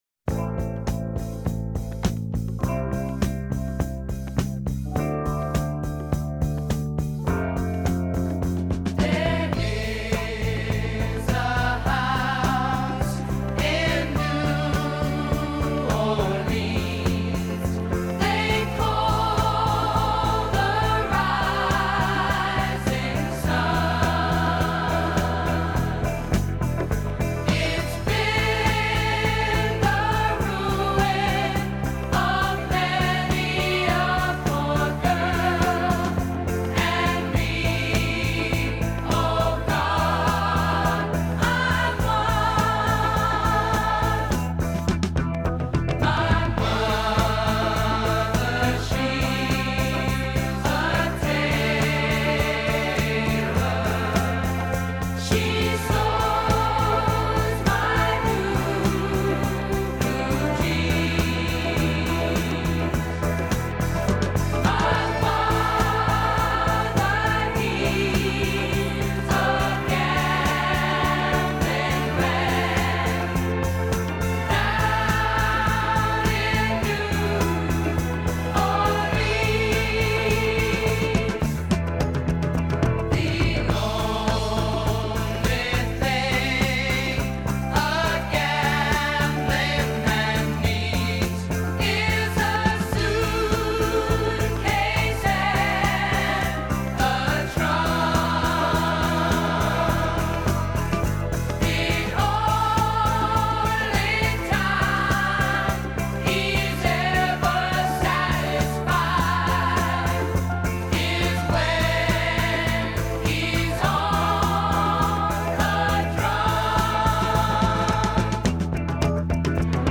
Хоровое исполнение.